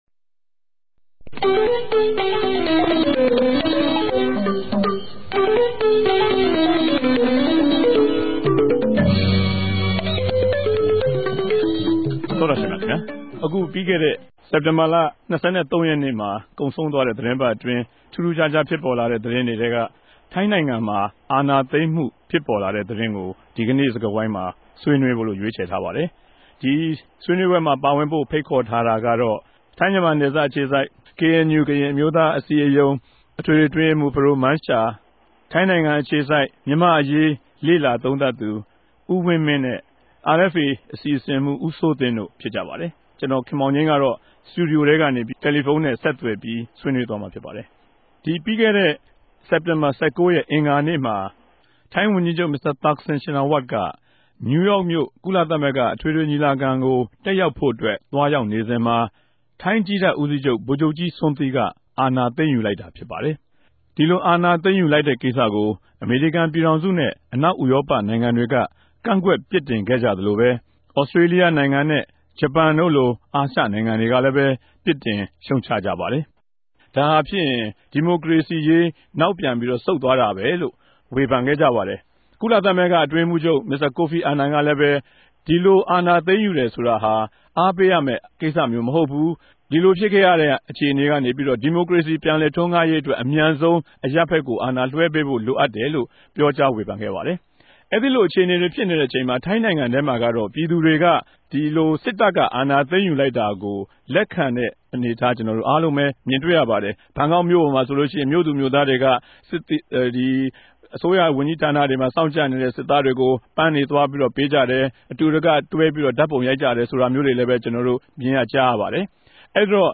ဝၝရြင်တန်္ဘမိြႚတော် RFAစတူဒီယိုထဲက